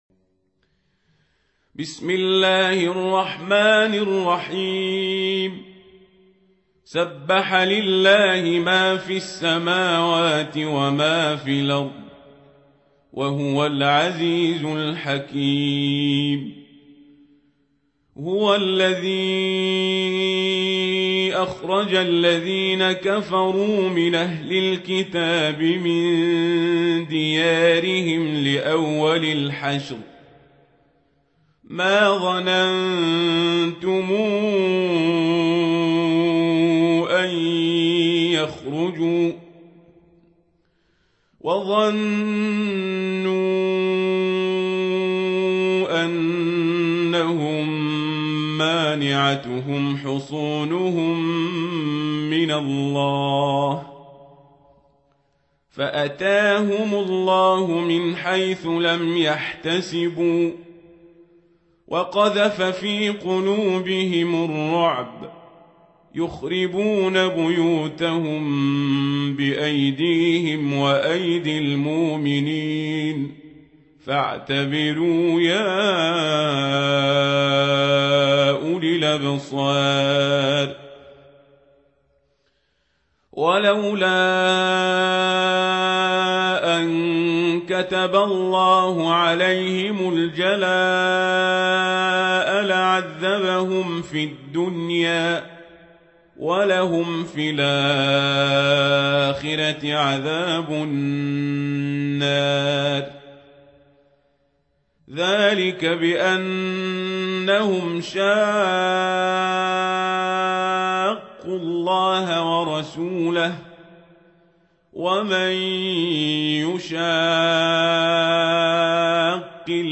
سورة الحشر | القارئ عمر القزابري